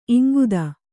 ♪ iŋguda